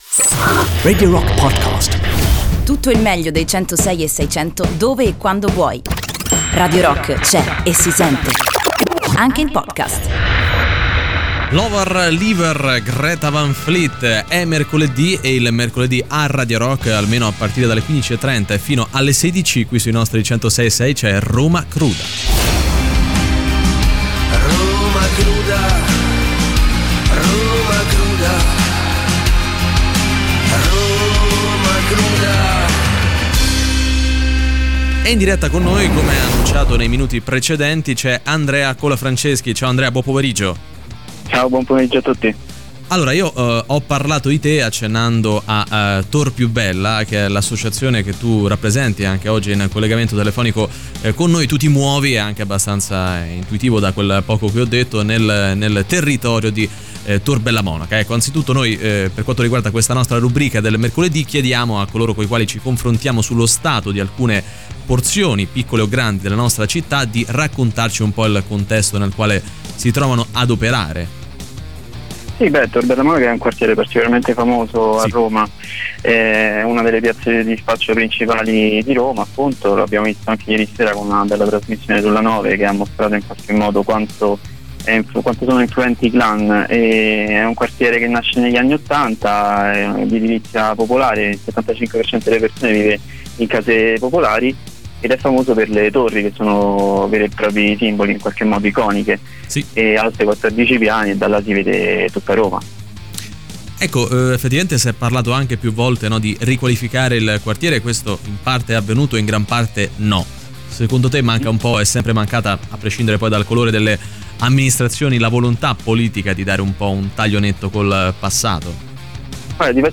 In diretta sui 106.6 di Radio Rock ogni mercoledì a partire dalle 15:30.